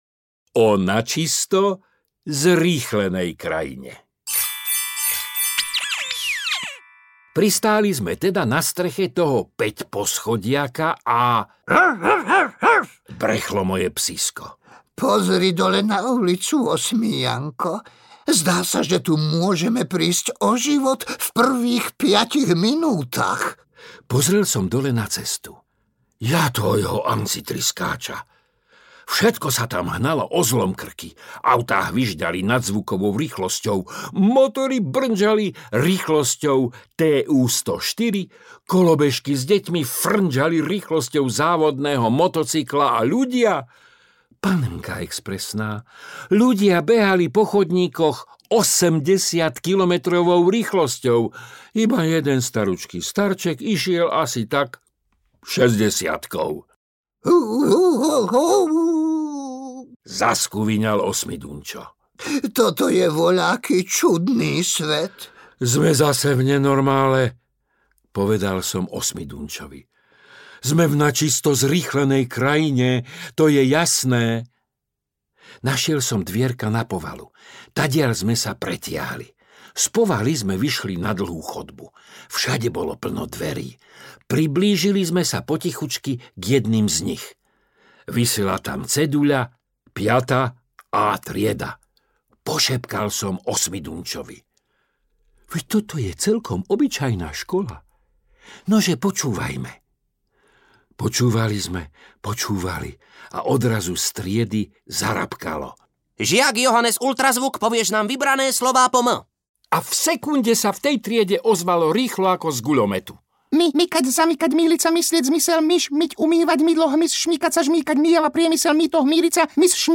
Osmijankove rozprávky audiokniha
Ukázka z knihy